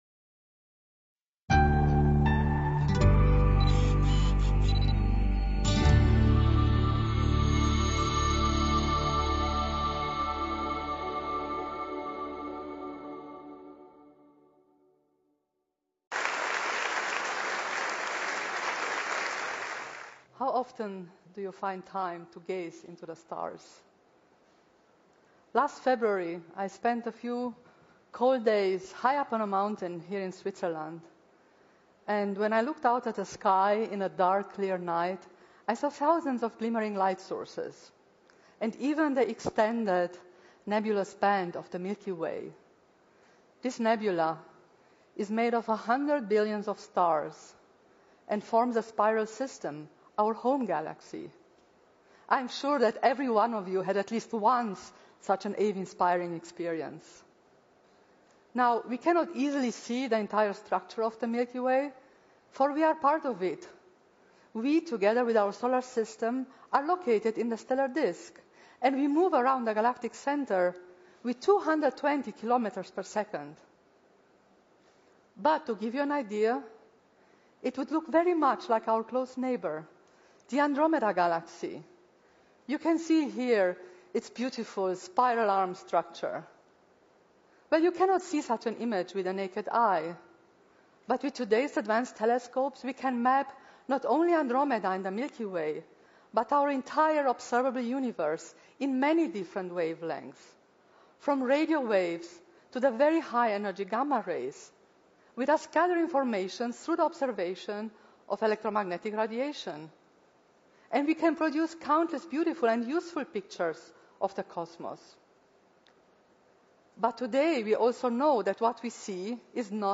TEDx Talk: Exploring The Vast Dark Universe at TEDxCERN Conference.